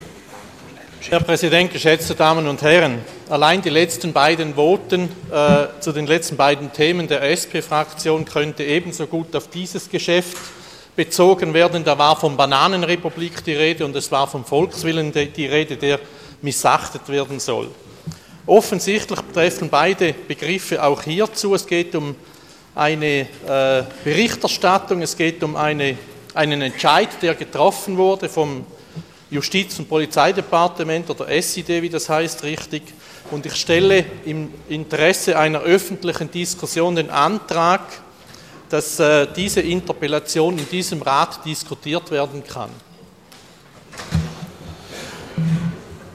Session des Kantonsrates vom 24. bis 26. November 2014